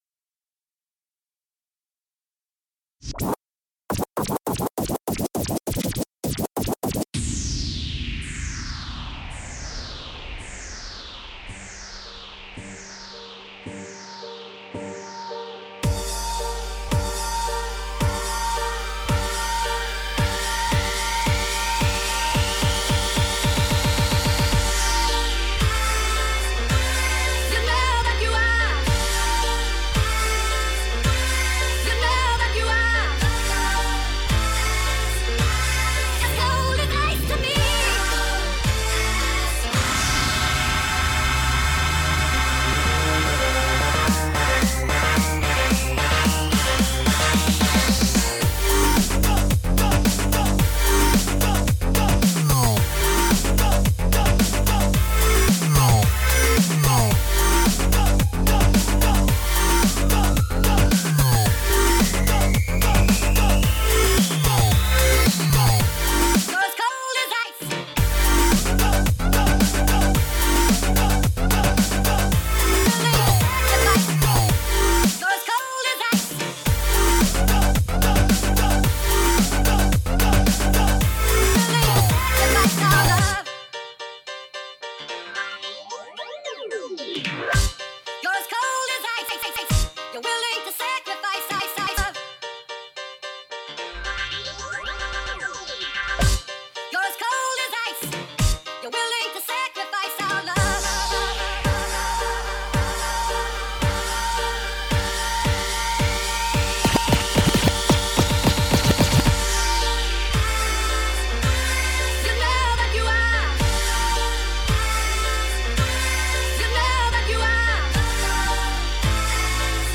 a furious 15 min booty dance pack